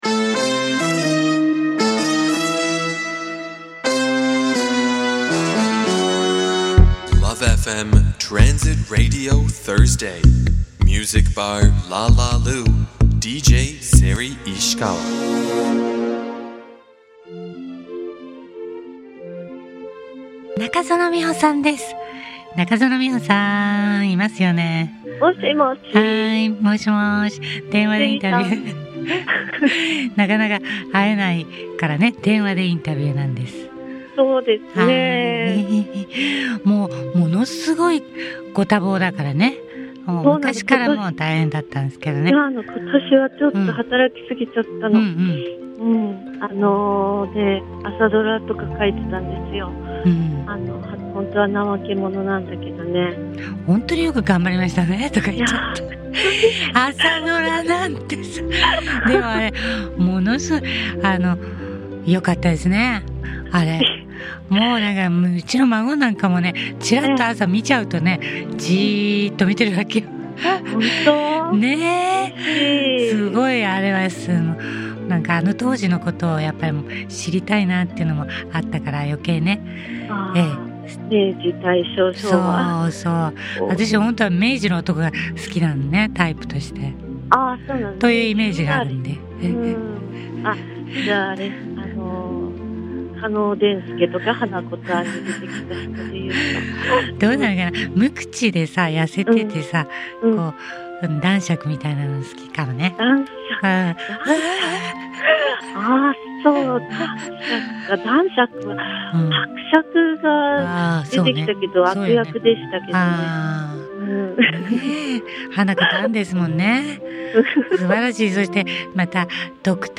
2015年1月29日放送分 ゲストあがた森魚②